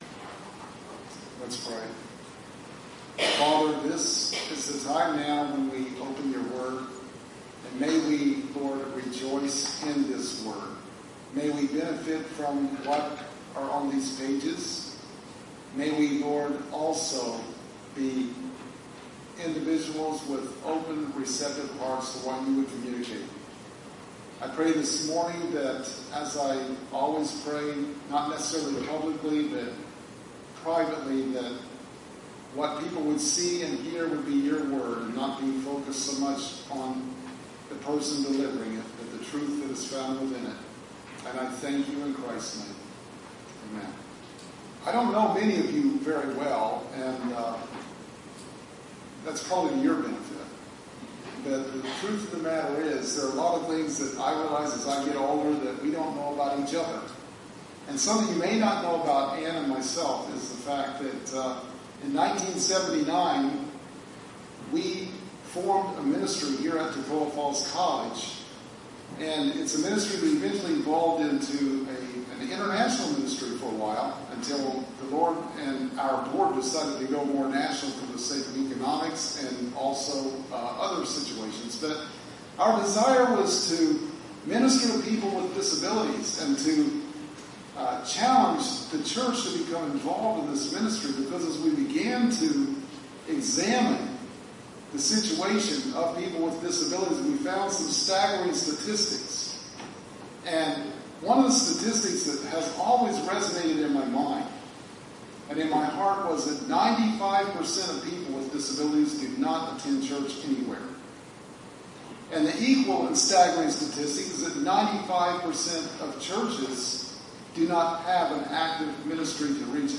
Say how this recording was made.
The services we post here were preached the previous week.